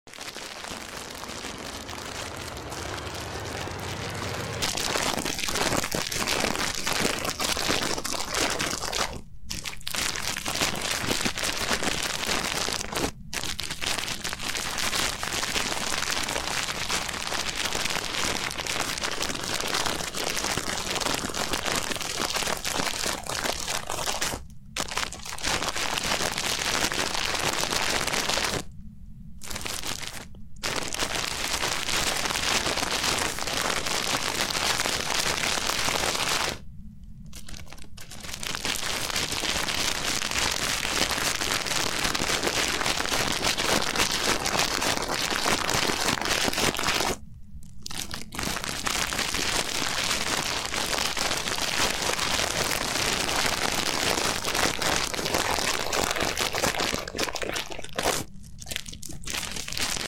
ASMR Rubbing Small Stones in sound effects free download
ASMR Rubbing Small Stones in a Plastic Bag | Soothing Scraping & Rustling Sounds
In this ASMR video, I gently rub small stones inside a plastic bag, creating soothing scraping and rustling sounds. The delicate friction between the stones offers a calming, textured audio experience, perfect for relaxation and tingles.